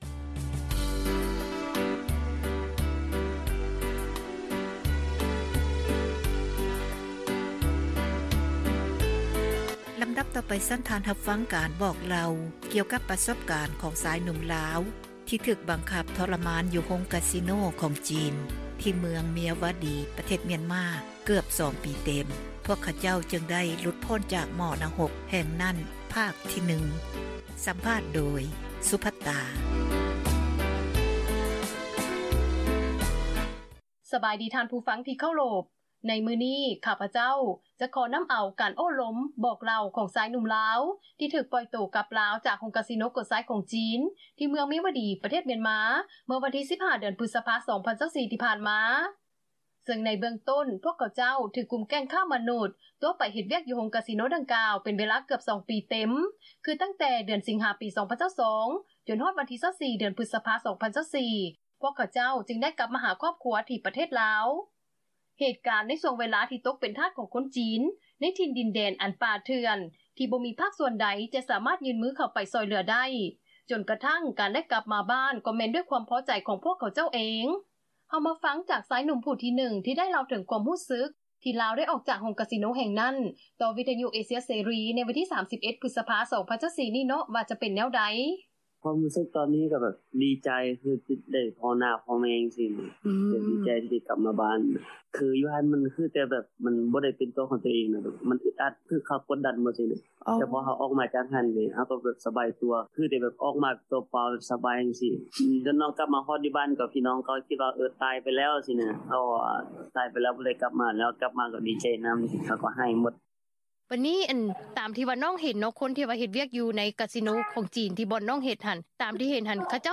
ສຳພາດ ຜູ້ເຄາະຮ້າຍ ຈາກການຄ້າມະນຸດ ໃນປະເທດມຽນມາ ຕອນທີ 1